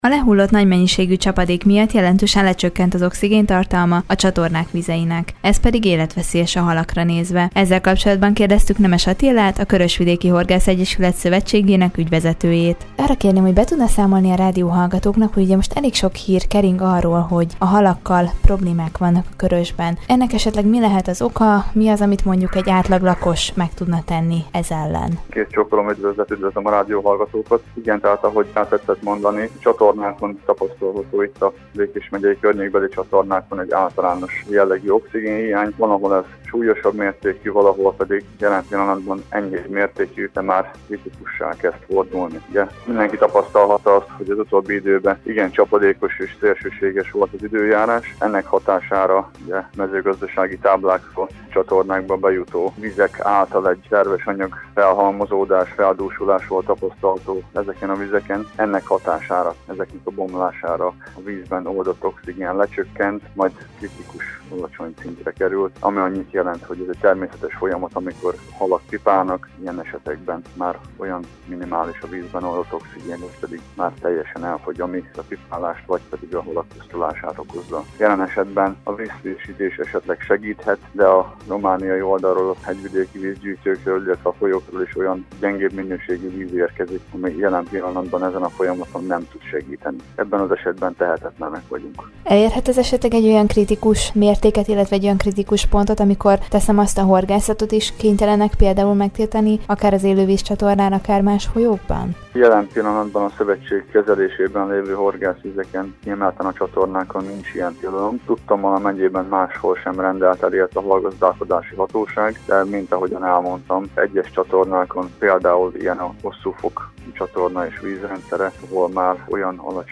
A lehullott nagy mennyiségű csapadék miatt jelentősen lecsökkent az oxigéntartalma a Körösök vizeinek. Ez pedig életveszélyes a halakra nézve. Ezzel kapcsolatban beszélgettünk